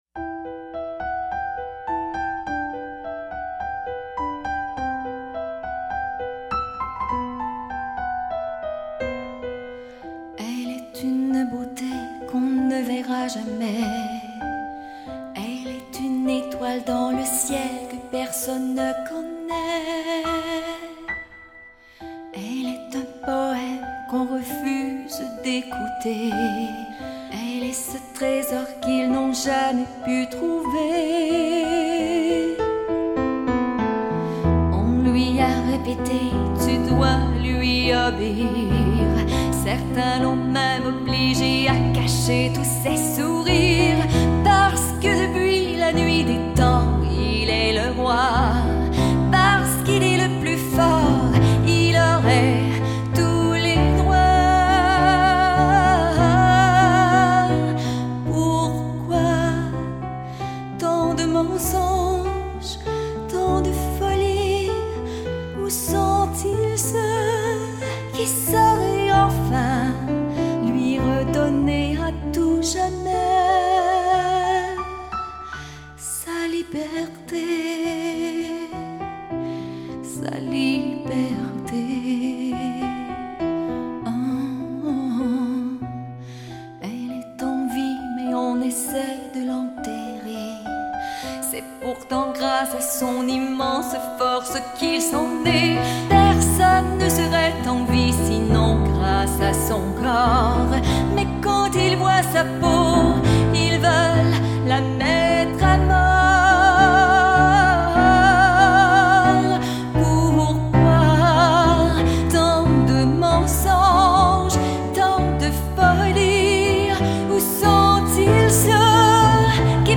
voix et piano
“live”